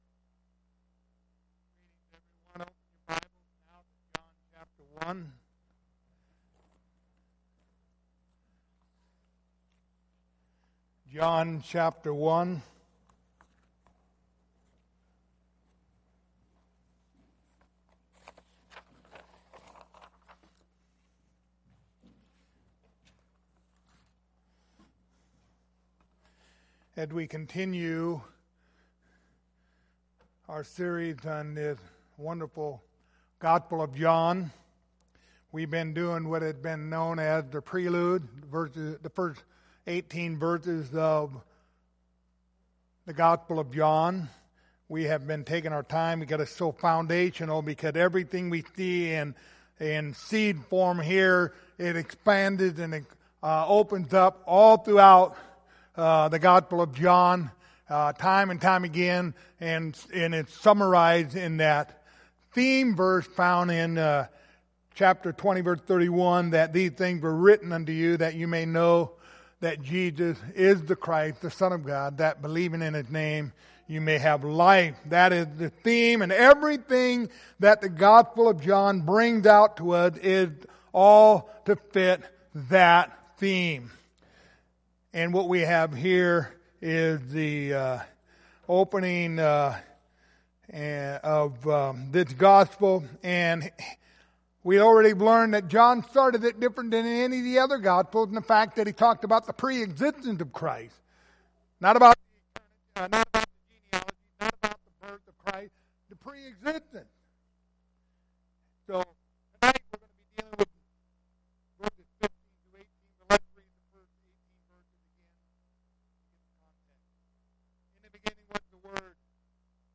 Passage: John 1:14-18 Service Type: Wednesday Evening